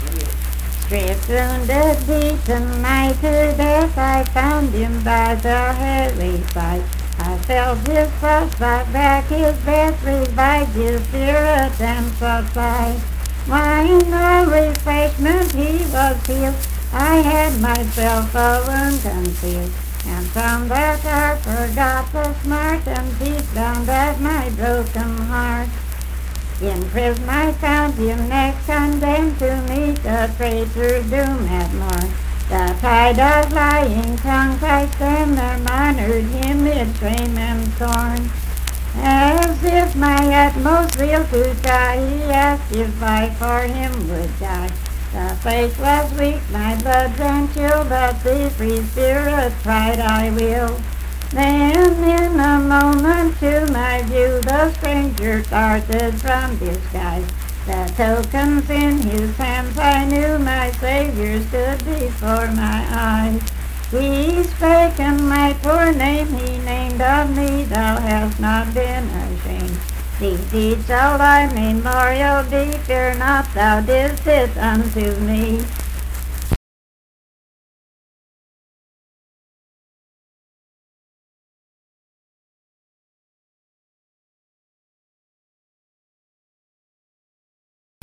Unaccompanied vocal music performance
Miscellaneous--Musical
Voice (sung)
Jackson County (W. Va.)